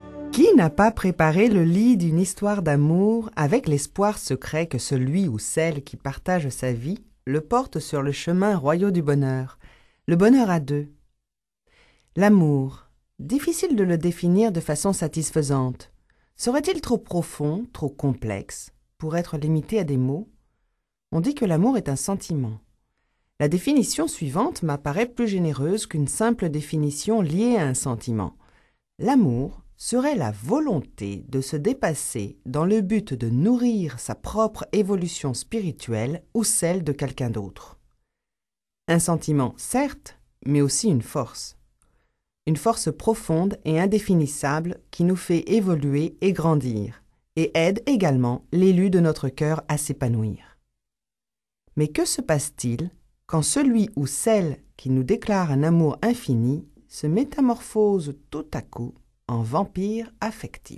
Ce livre-audio se propose d'étudier au quotidien les méfaits et les conséquences d'une relation amoureuse destructrice avec un manipulateur.